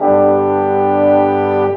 Rock-Pop 01 Brass 05.wav